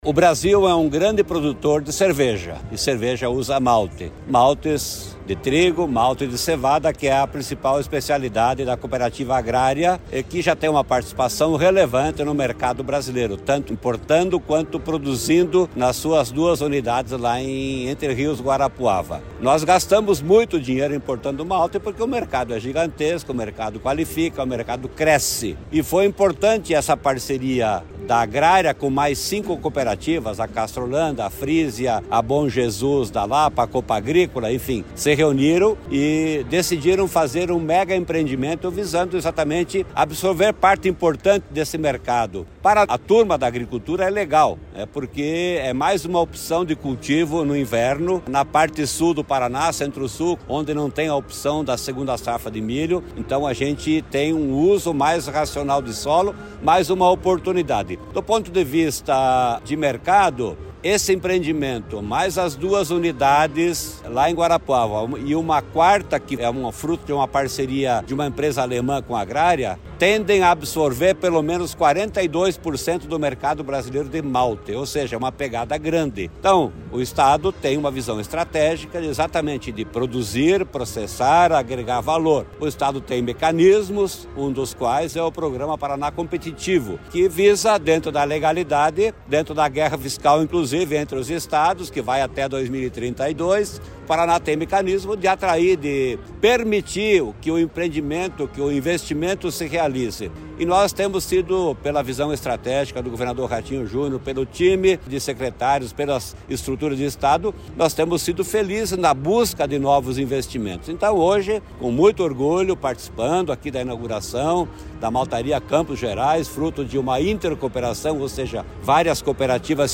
Sonora do secretário da Fazenda, Norberto Ortigara, sobre a inauguração da Maltaria Campos Gerais